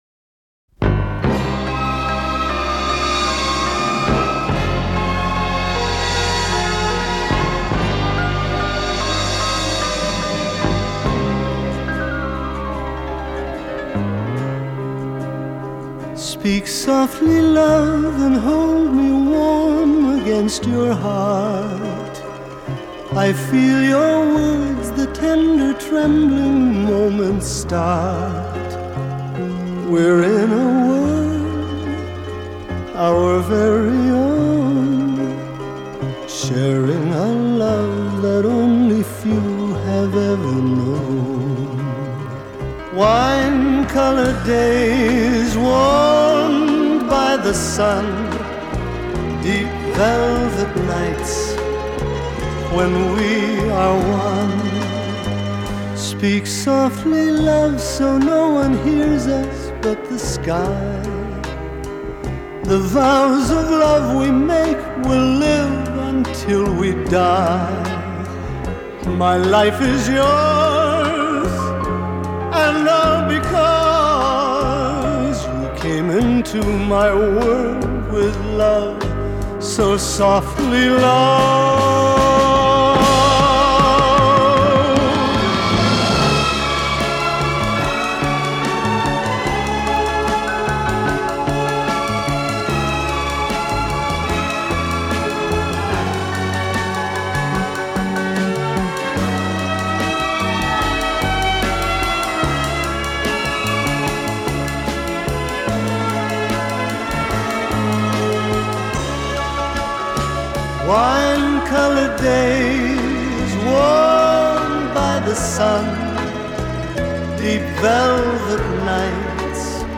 歌曲曲调优美，朗朗上口，也许您可以惬意地哼上几句！